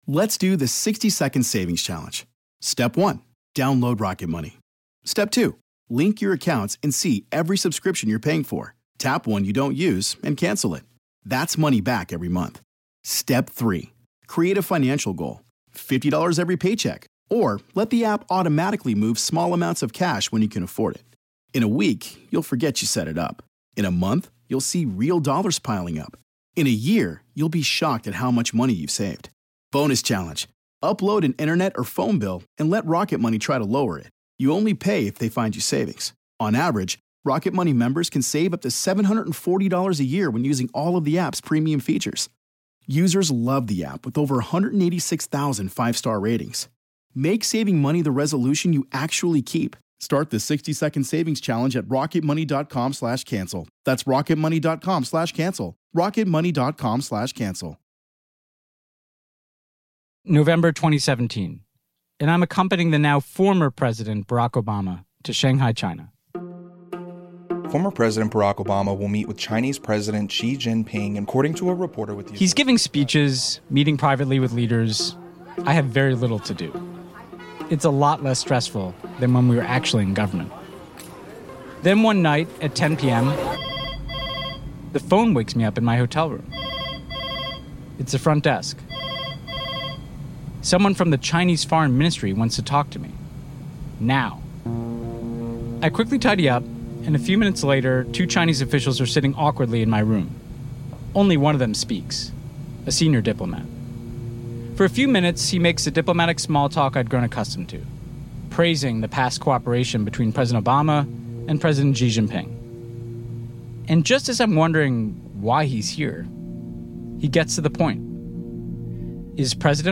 Host Ben Rhodes talks to experts, human rights activists and Hong Kong protesters about the nature of China’s model, and what America should think - and do - about it.